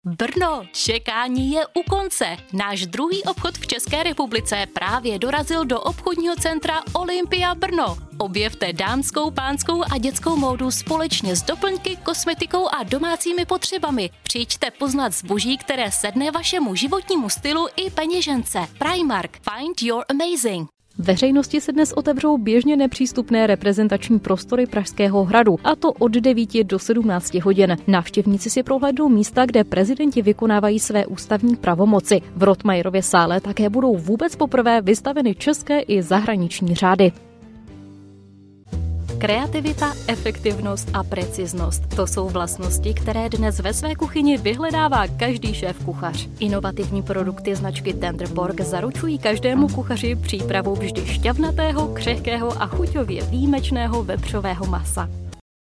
Czech, Eastern European, Female, Home Studio, 20s-30s.